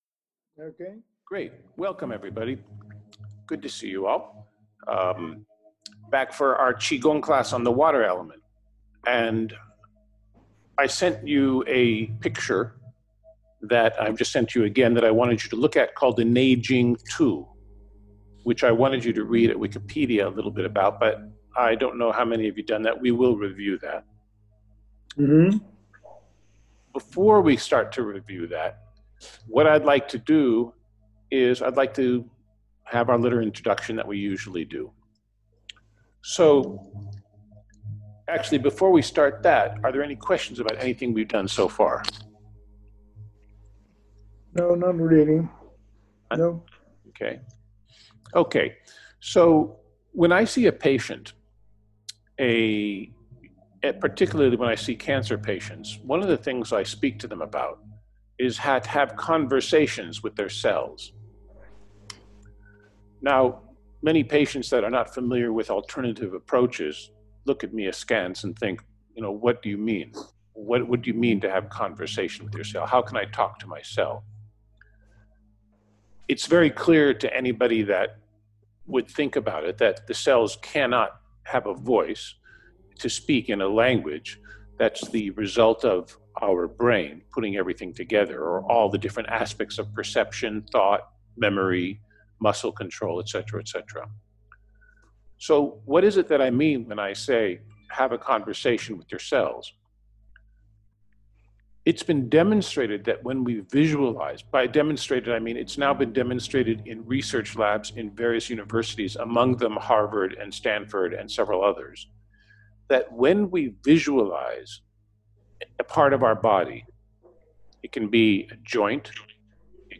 The Water Element is related to Winter and to the Kidneys and Urinary Bladder in Traditional Chinese Medical Theory, Listen to this excerpt from the discourse and if you are interested please feel free to join us next time.